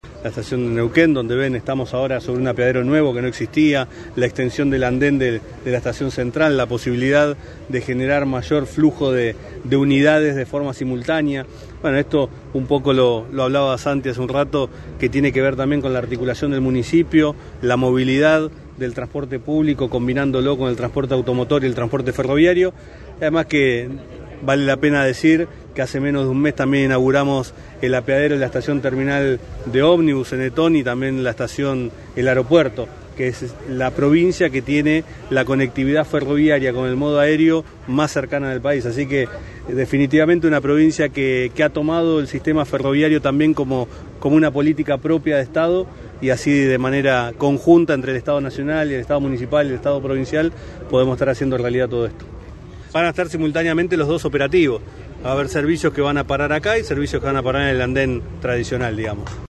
Martín Marinucci, presidente de Trenes Argentinos.